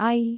speech
syllable
pronunciation